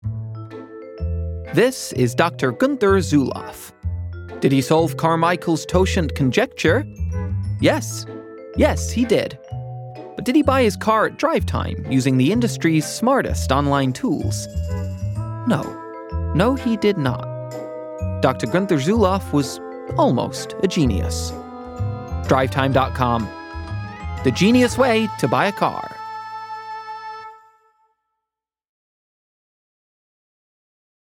Drivetime - US, Expressive, Engaging